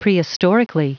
Prononciation du mot prehistorically en anglais (fichier audio)
Prononciation du mot : prehistorically